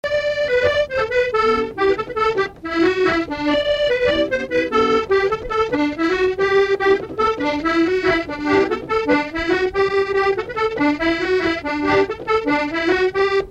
Mémoires et Patrimoines vivants - RaddO est une base de données d'archives iconographiques et sonores.
Couplets à danser
branle : courante, maraîchine
Pièce musicale inédite